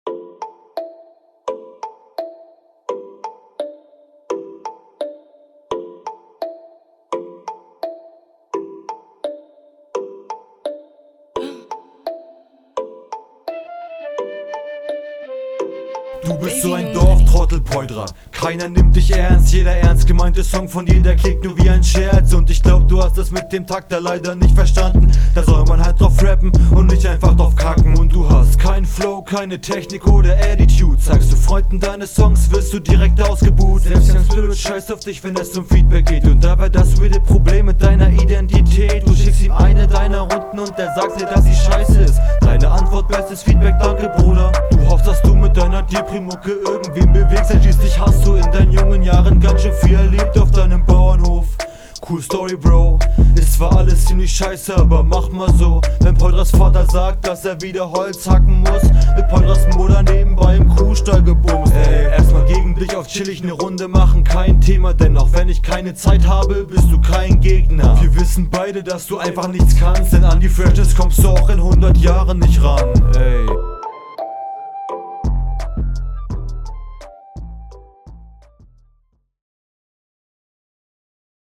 Du hast leider ein paar kleine Haspler drin.